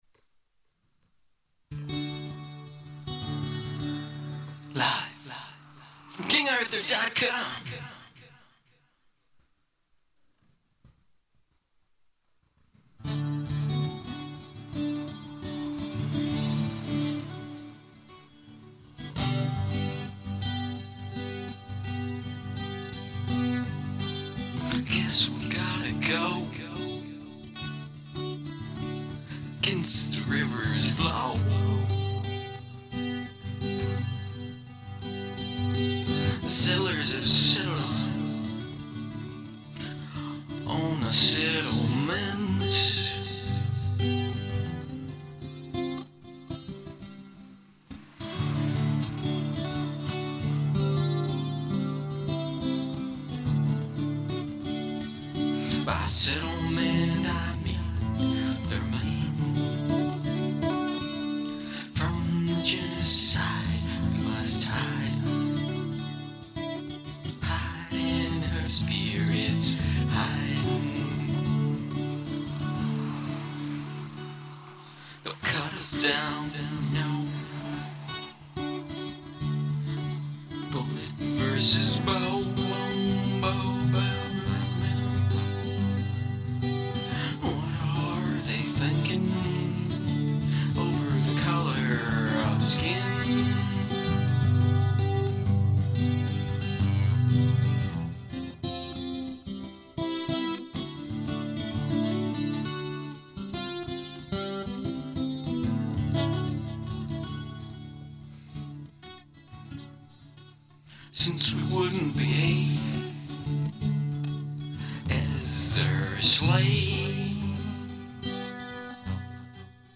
Chords — Fingered
Vocals, Guitar